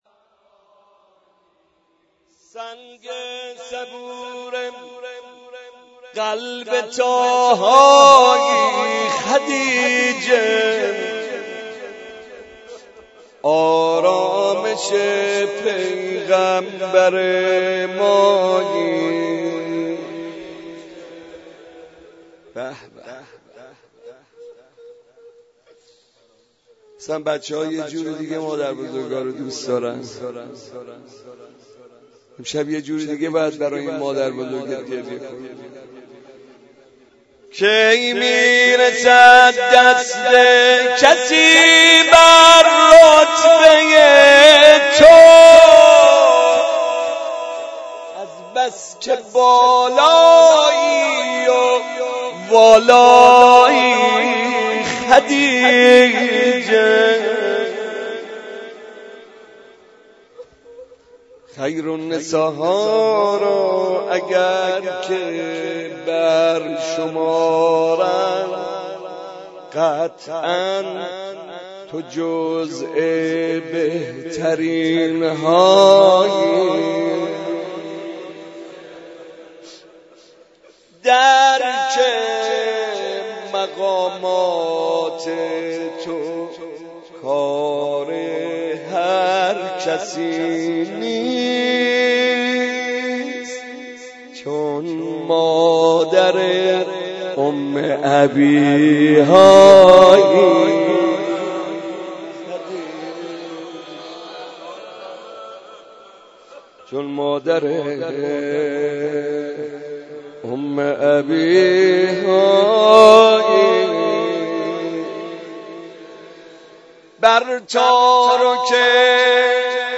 مراسم شب دهم ماه مبارک رمضان
مداحی